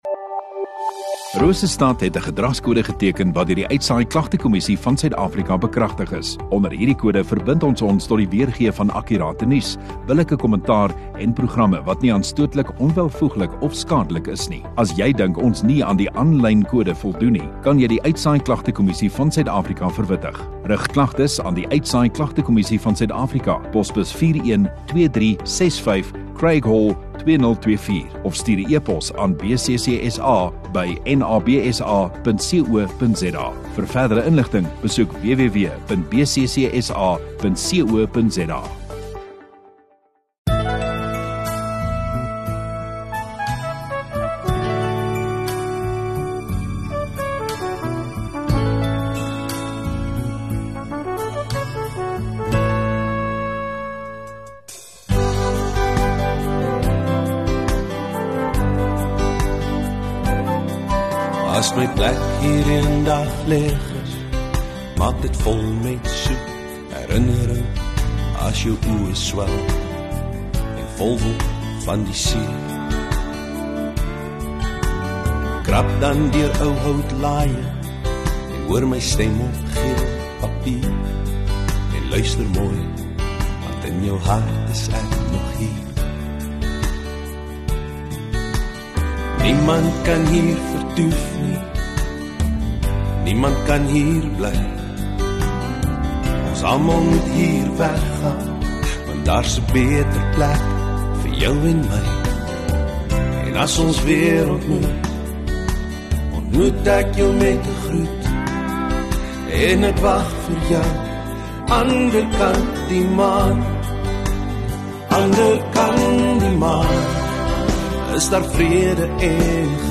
27 Jul Saterdag Oggenddiens